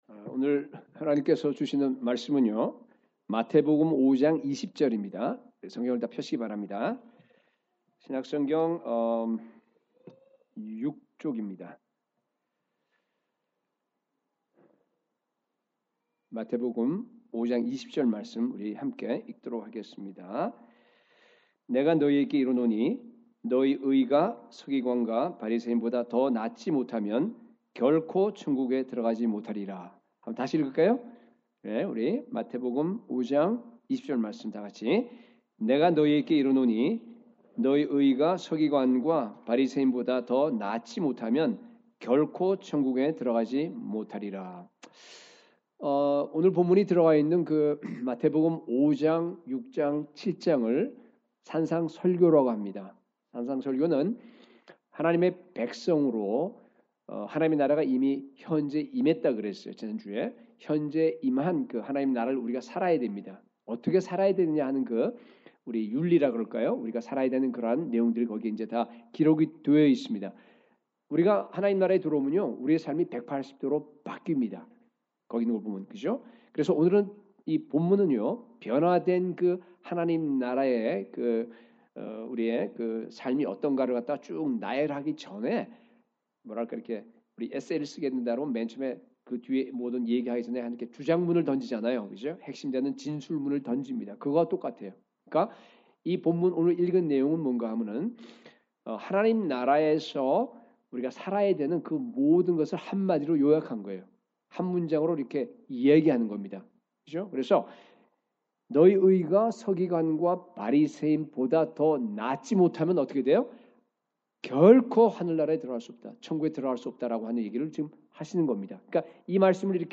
Like this: Like Loading... 2018년 주일설교 2026년 전교인 수련회 찬양 플레이 리스트 각종 신청서 2025년 헌금내역서 신청서